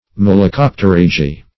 Malacopterygii \Mal`a*cop`te*ryg"i*i\, prop. n. pl. [NL., fr.